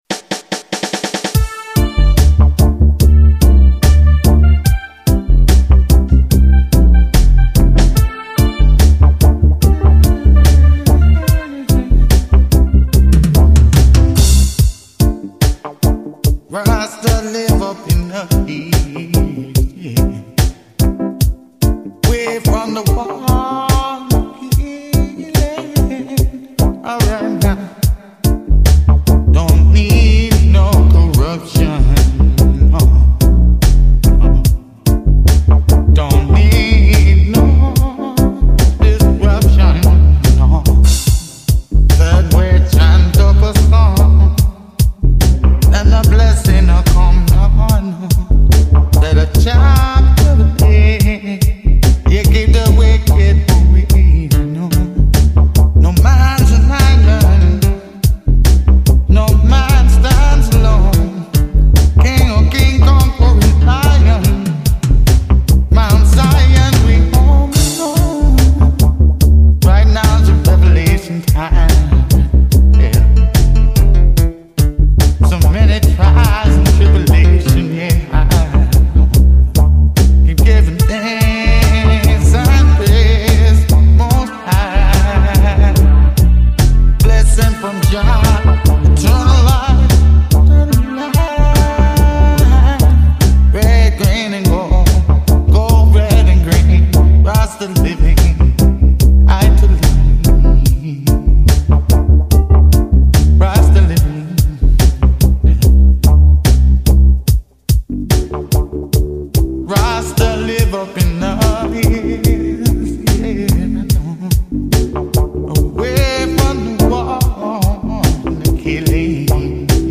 vocal
riddim